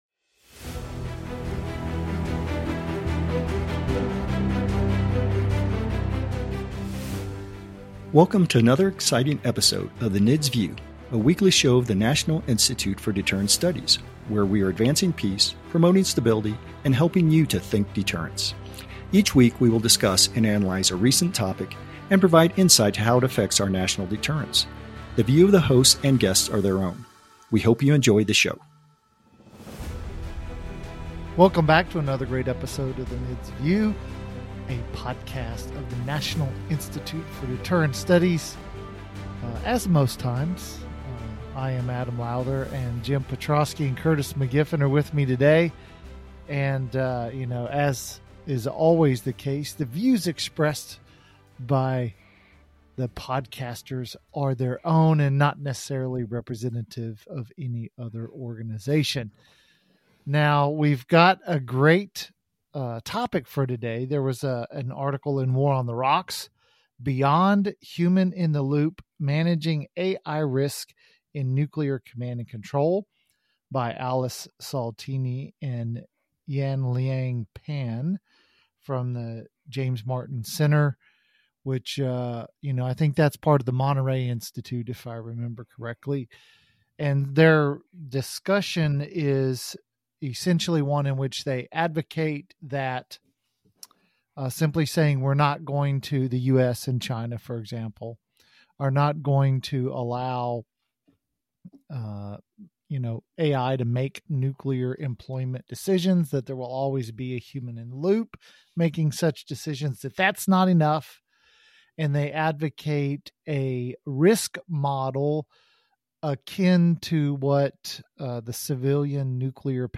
For each episode we pick a current deterrence topic and have a cordial, exciting and sometimes funny discussion.